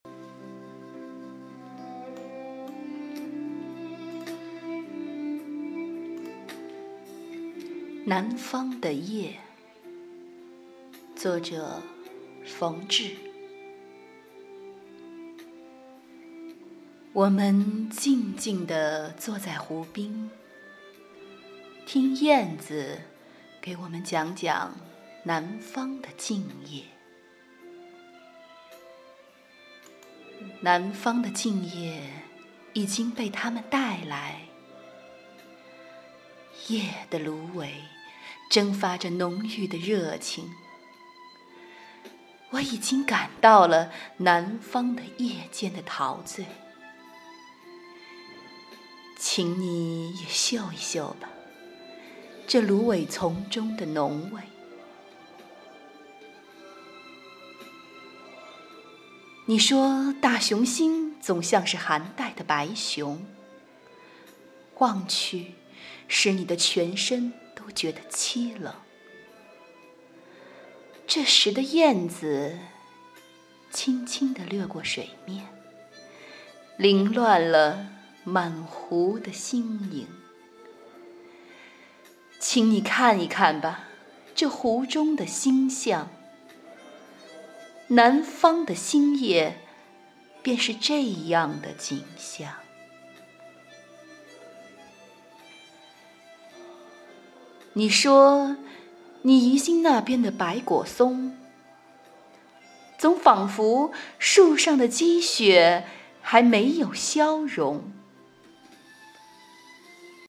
朗诵作品——南方的夜